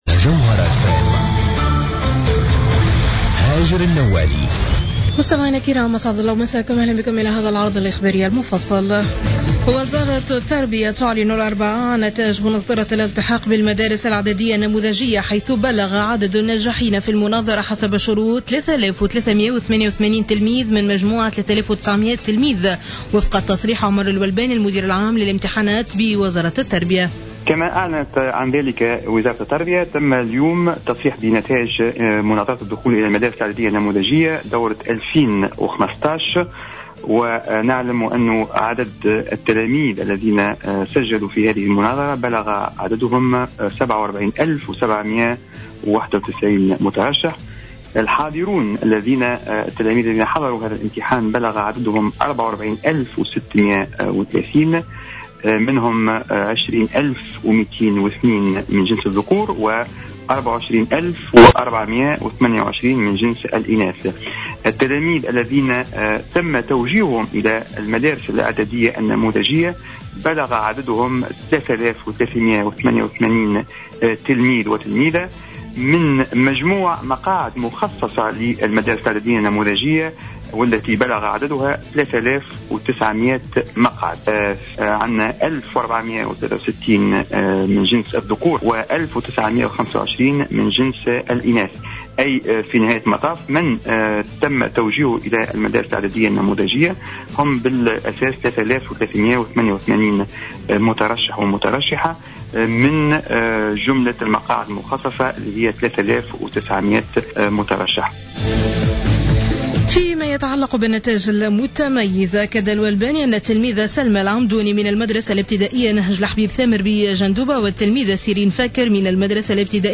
نشرة أخبار منتصف الليل ليوم الخميس 16 جويلية 2015